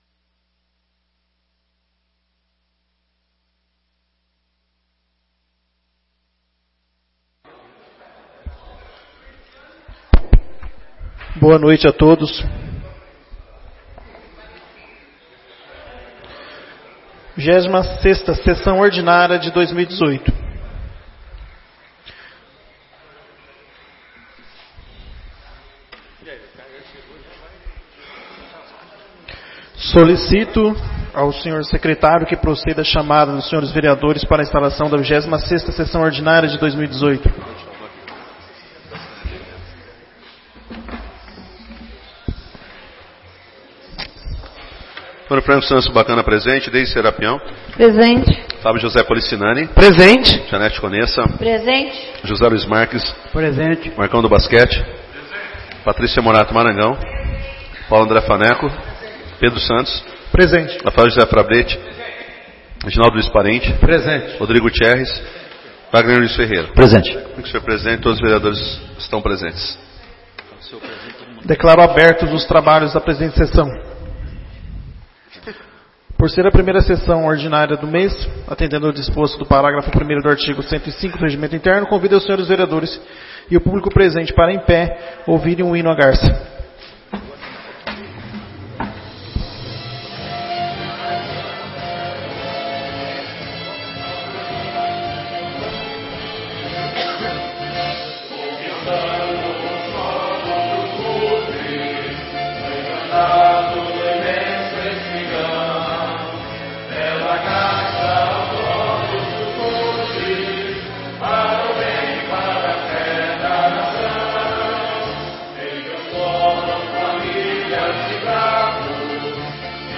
26ª Sessão Ordinária de 2018